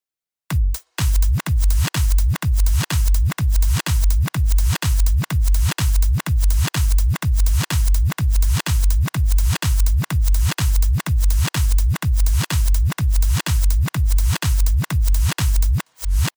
これをもとにホストスタートでリバースをかけてみましょう。
次にホストスタートのオフセットを少し上げてみましょう。
こうすることでちょっとハネた感じが表現できますね。